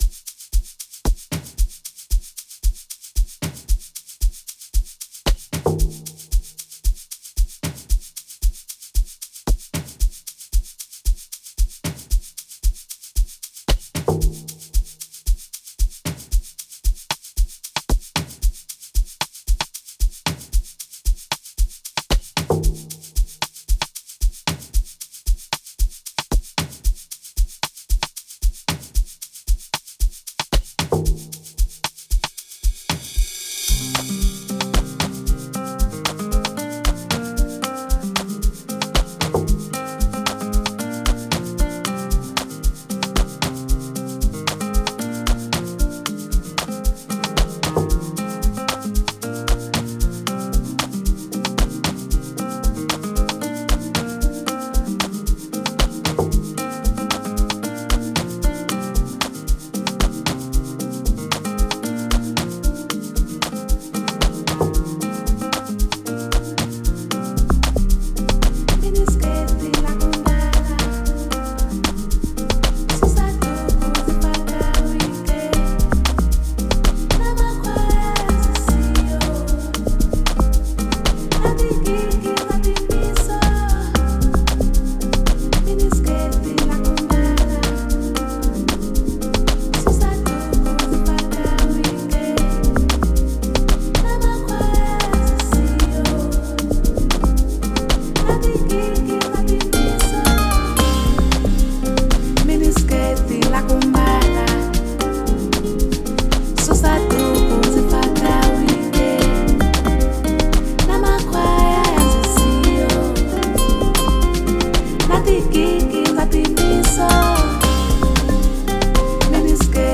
slide and glide, freely flexing their vocals.